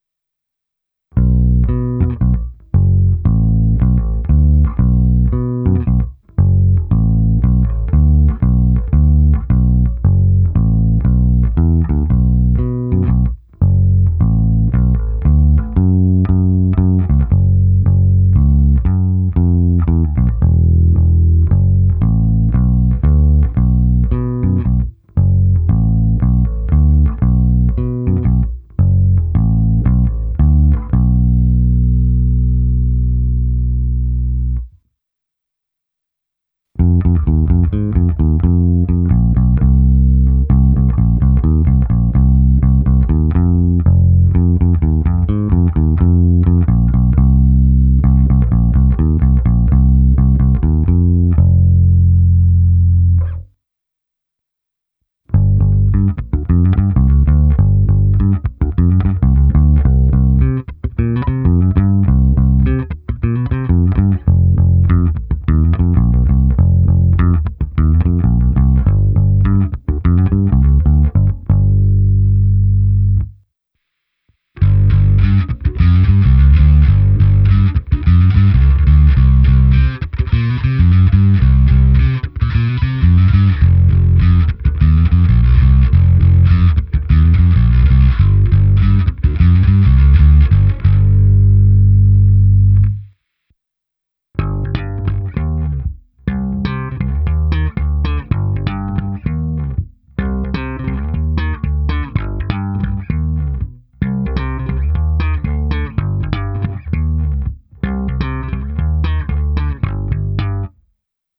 Zvuk je tučný, na jeho pozadí i přes tupé struny slyším zvonivé vrčení typické právě pro modely 1957-1959.
Abych simuloval, jak asi hraje baskytara přes aparát, použil jsem svůj pedalboard s preampem Darkglass Harmonic Booster, kompresorem TC Electronic SpectraComp a preampem se simulací aparátu a se zkreslením Darkglass Microtubes X Ultra. V nahrávce jsem použil i zkreslení, a přestože ty struny jsou opravdu bídné, tak i trochu slapu.
Ukázka se simulací aparátu